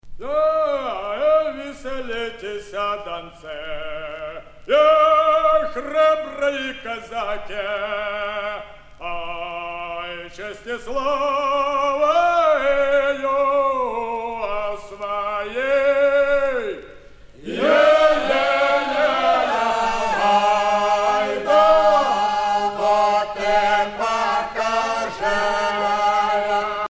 Russian folksongs
Pièce musicale éditée